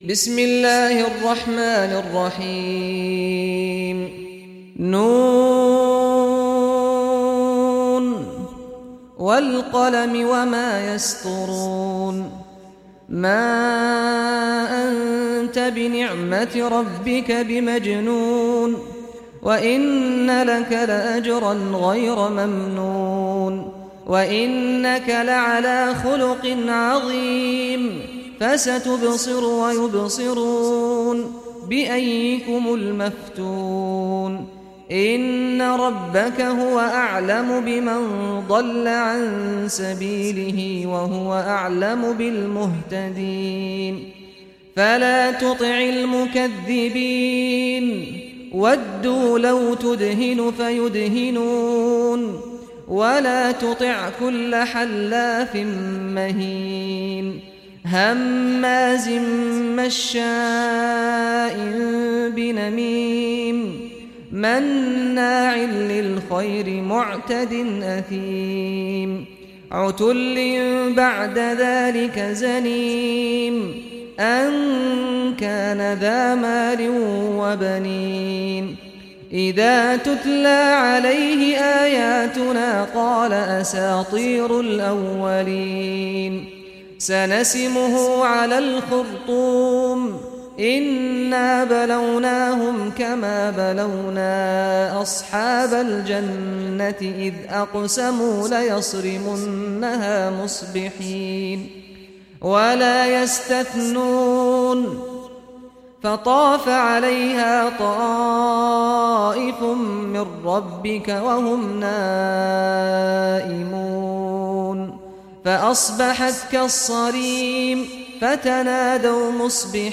Surah Al-Qalam Recitation by Sheikh Saad al Ghamdi
Surah Al-Qalam, listen or play online mp3 tilawat / recitation in Arabic in the beautiful voice of Sheikh Saad al Ghamdi.